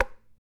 CHARANGNOISE.wav